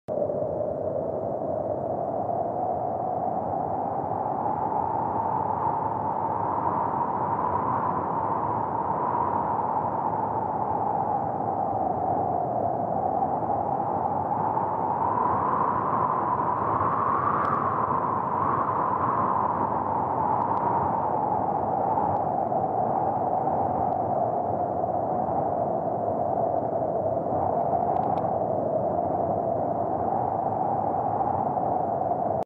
Beep Sound Button - Bouton d'effet sonore